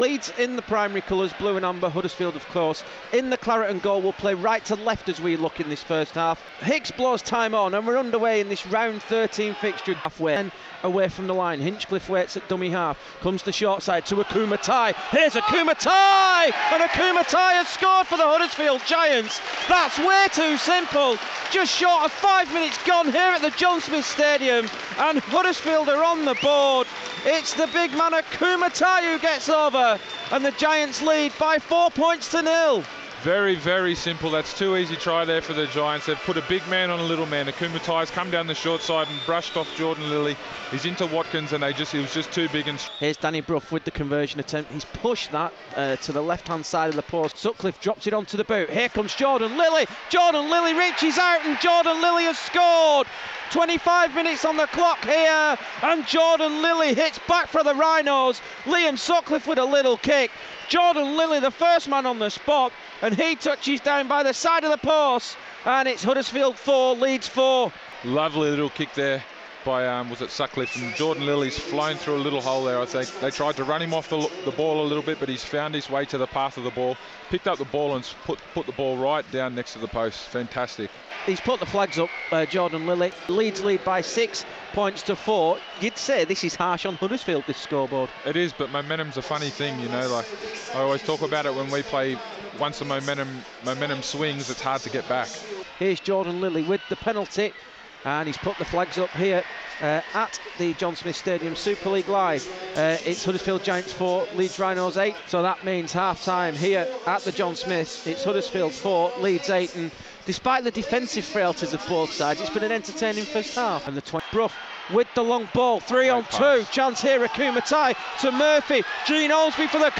Highlights of Radio Yorkshire's commentary of Huddersfield Giants 3rd win of the season as they hand Leeds Rhinos their 10th defeat of the season.